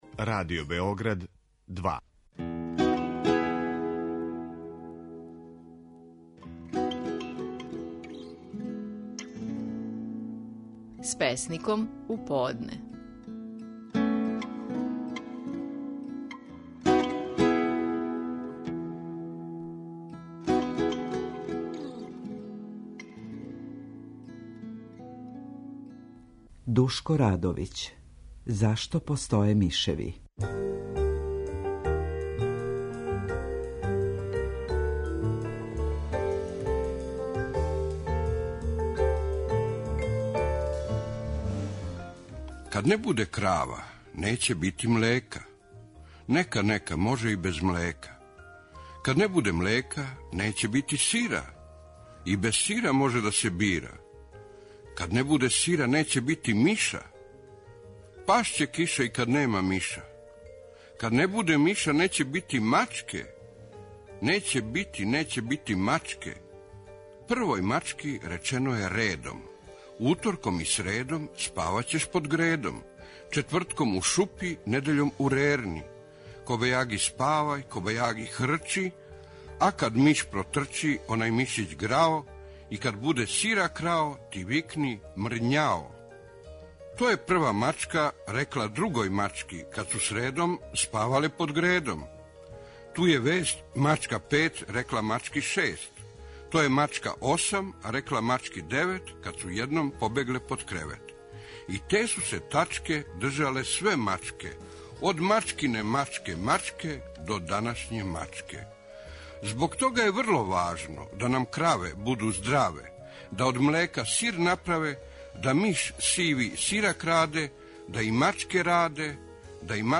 Стихови наших најпознатијих песника, у интерпретацији аутора.
Душко Радовић говори своју песму "Зашто постоје мишеви".